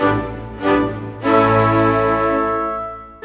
Rock-Pop 06 Orchestra 04.wav